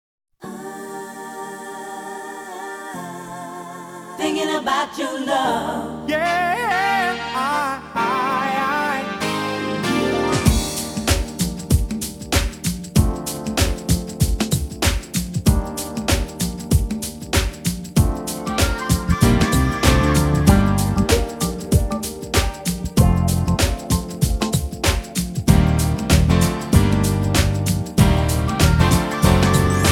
• R&B/Soul
English soul singer